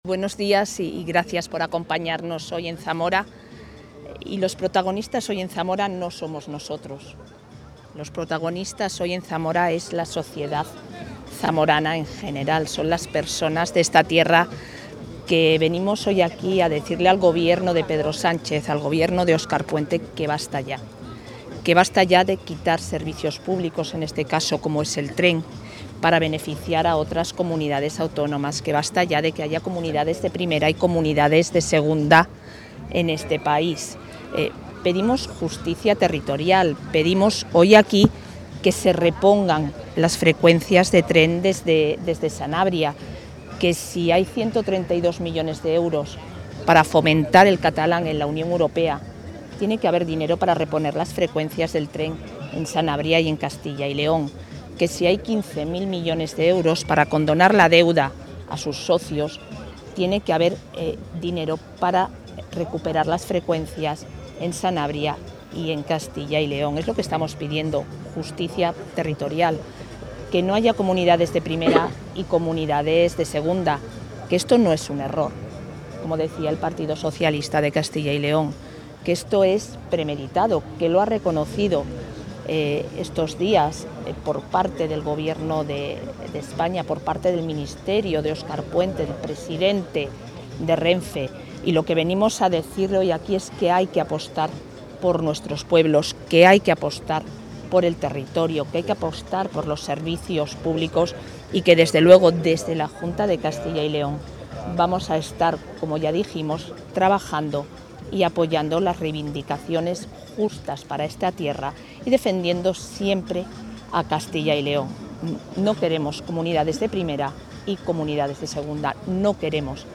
La vicepresidenta de la Junta participa en la concentración a favor del mantenimiento de las paradas de tren en Zamora
Declaraciones de la vicepresidenta de la Junta.